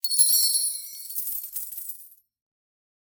coins.aac